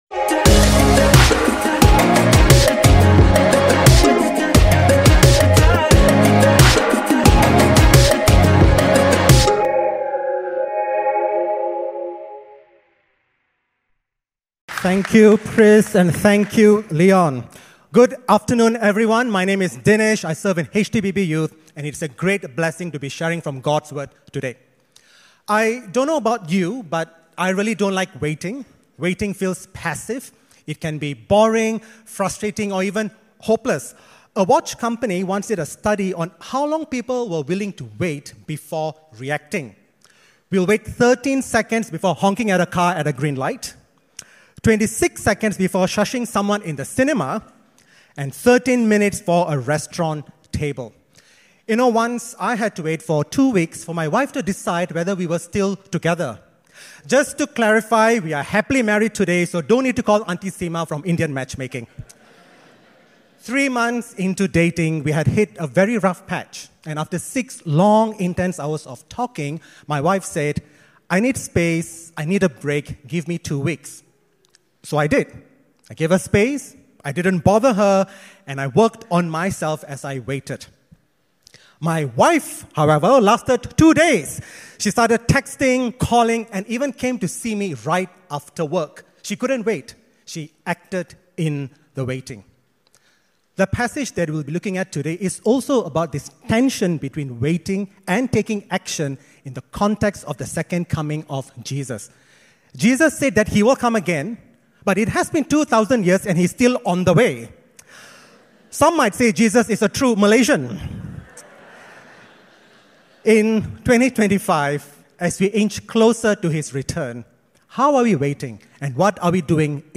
SERMON+ENG_10+AUG.MP3